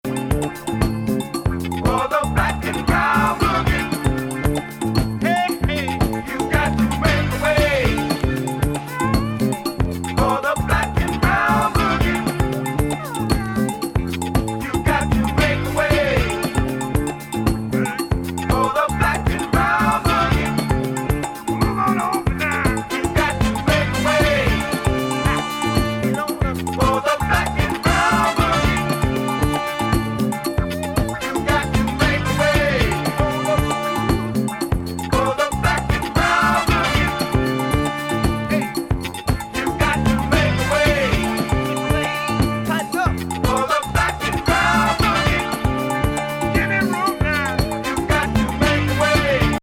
しそう!!MELLOW～FUNKYまで、どこを取っても素晴らしい。